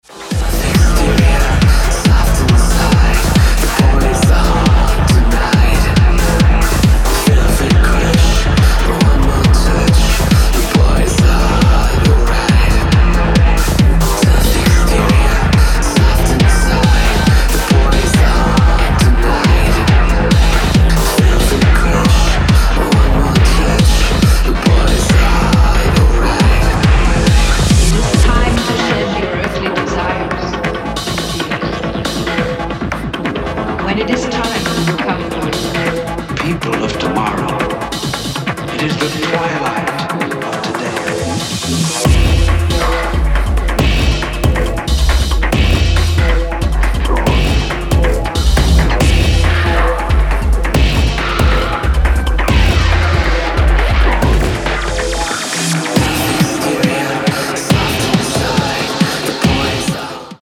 ダークで狂騒的なハウス/テクノを展開しています。